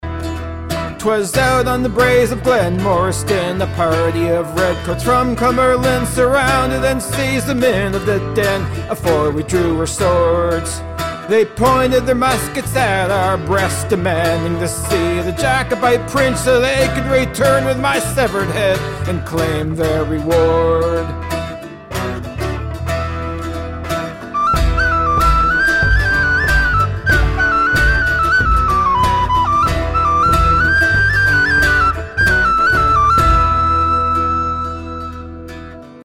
- historical piece, done in Celtic Rock style